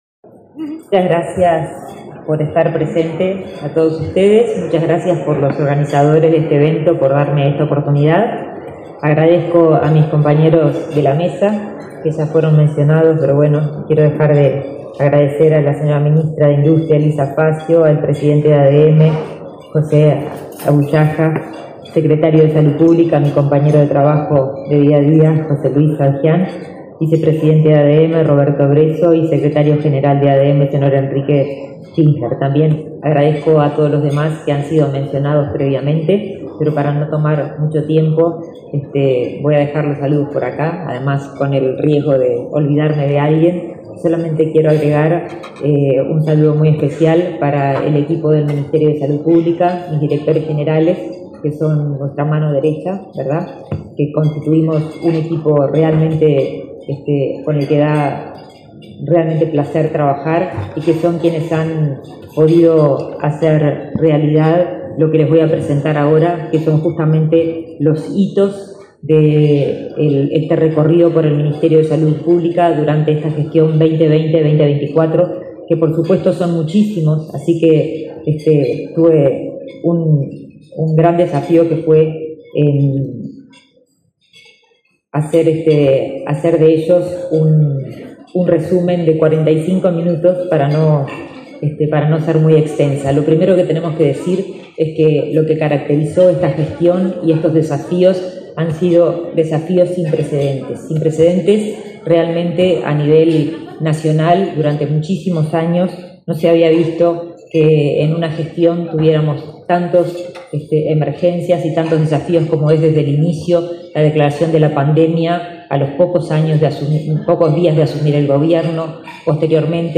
Palabras de la ministra de Salud Pública, Karina Rando, en ADM
La ministra de Salud Pública, Karina Rando, participó, este 12 de junio, en el almuerzo de trabajo de la Asociación de Marketing del Uruguay (ADM).